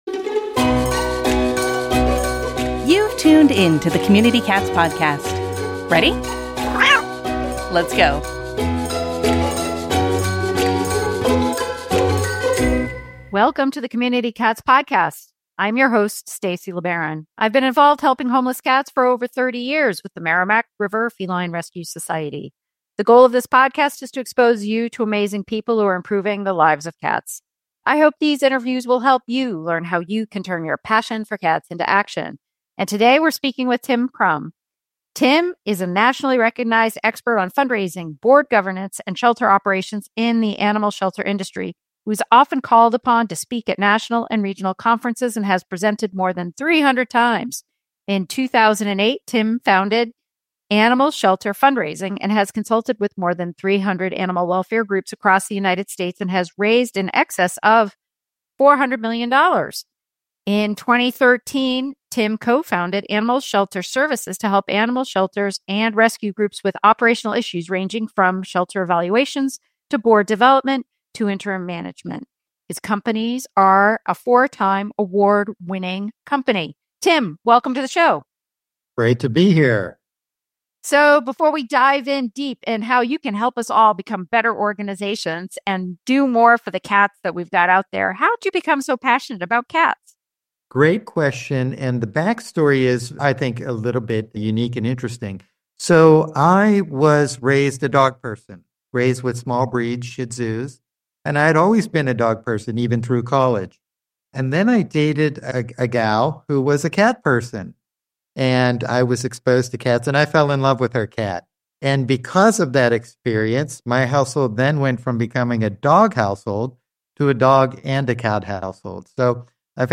In this thought-provoking conversation